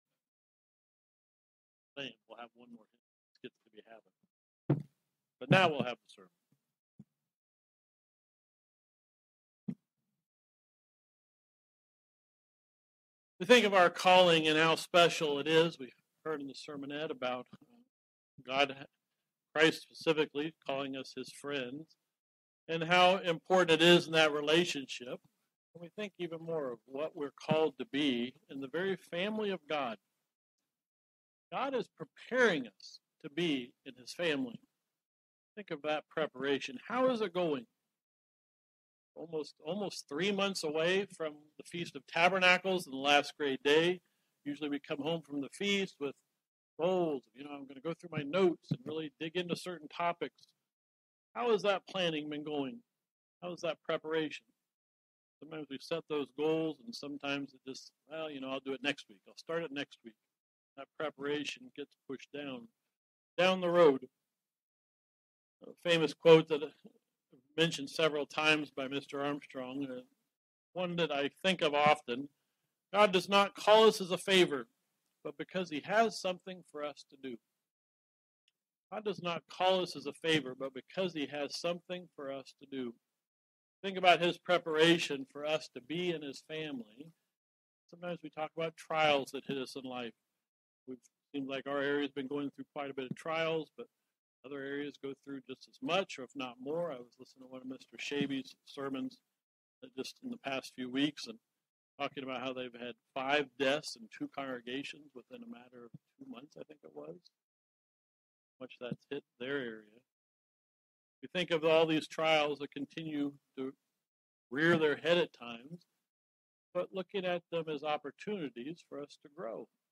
God doesn't call us as a favor but because He has something for us to do. This sermon looks at an example of an individual that was prepared for a big trial in his life.